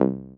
Uisynth5simpleC x3.wav